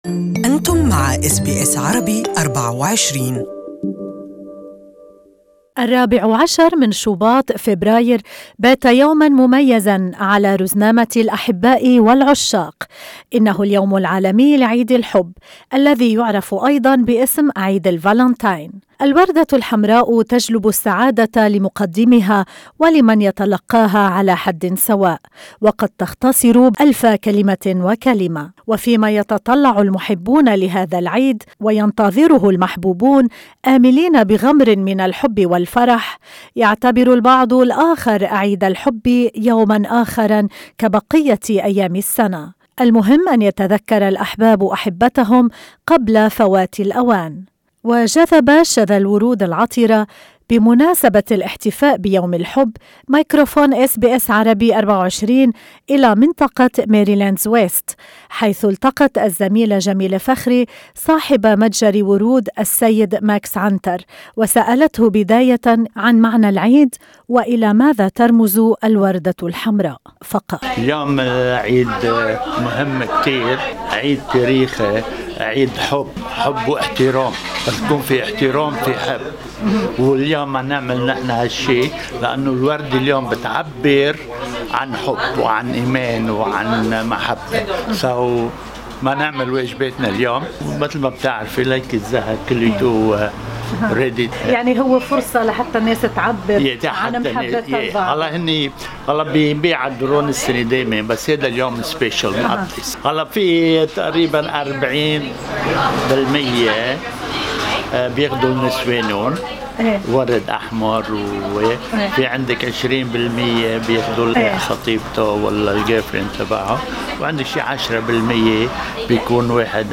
استمعوا الى المقابلة مرفقة بالصورة أعلاه.